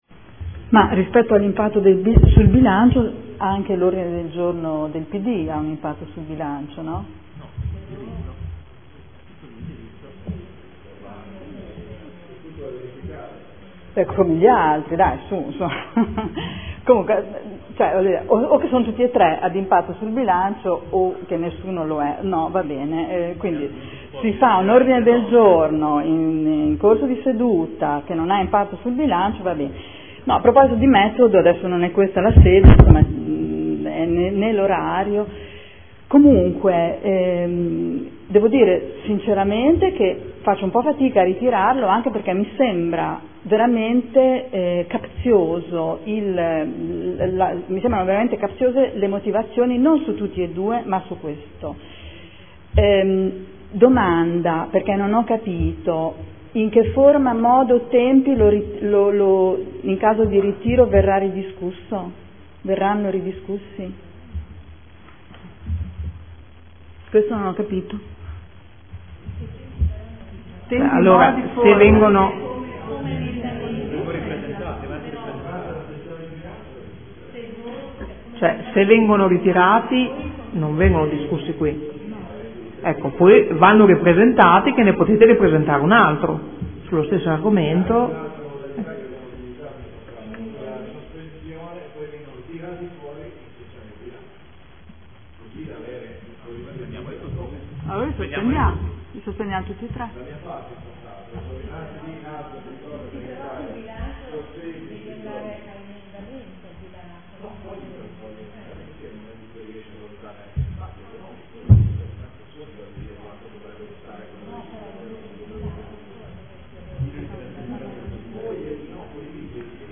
Seduta del 13/11/2014 Dibattito. Ordini del giorno trasporto scolastico.